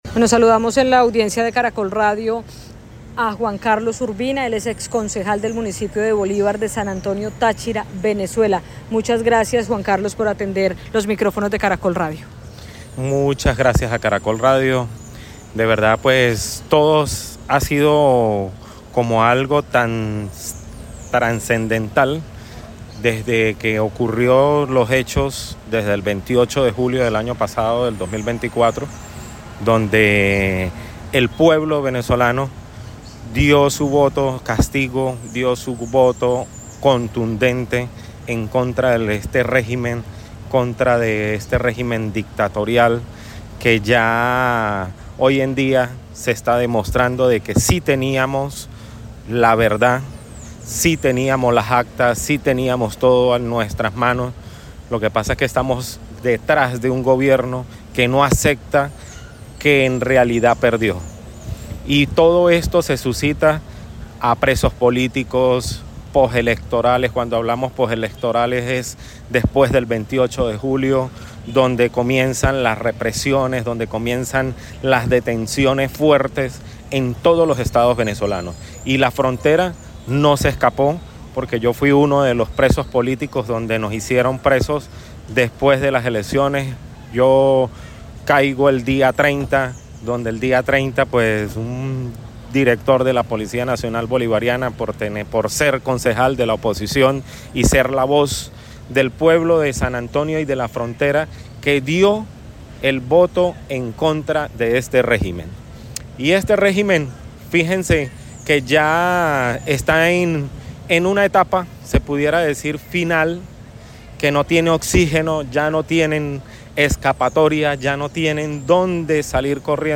El conmovedor testimonio de un político venezolano sobre la vida después de la cárcel
Juan Carlos fue concejal de San Antonio del Táchira, Venezuela y relató entre el llanto y la esperanza sus siete meses de detención